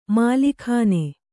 ♪ mālikhāne